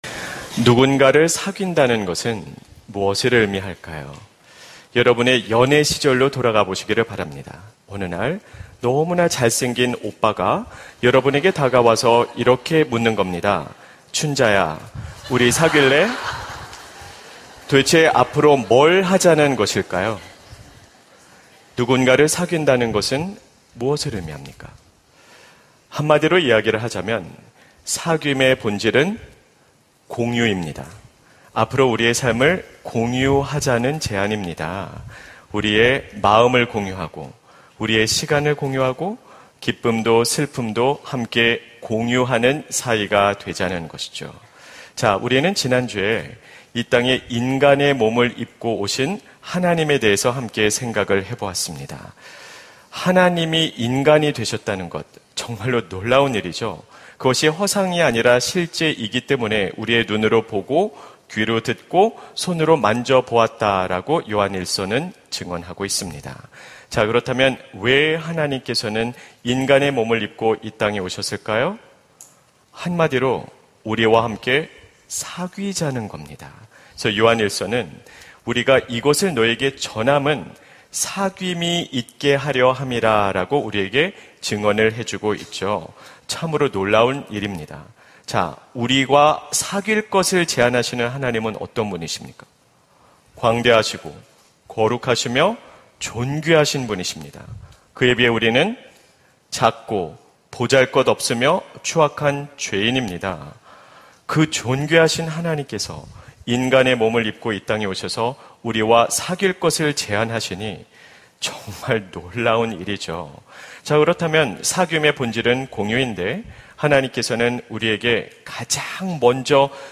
설교 : 수요향수예배 하나님의 숨결이 스며드는 일상 - 우리 사귈까요? 설교본문 : 요한1서 1:5-10